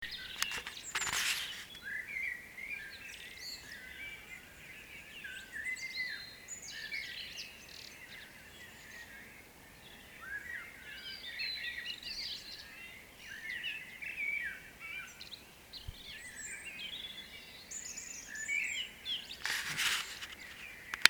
A digital dictation machine was borrowed well in advance (couldn’t think how else to record it as my phone is from the dark ages) and a few practice runs attempted – not at ridiculous o’clock in the morning of course, but in more civilised early evenings.
According to the RSPB website the chorus begins about an hour before sunrise, so by 4am I was up and out and sitting in splendid isolation in the garden.
It was still pretty much dark, but he was singing his little socks off – and really loud. I know I’ve heard blackbirds sing before but I’ve never consciously sat and just listened to one – beautiful but definitely loud. Here’s a brief recording of him.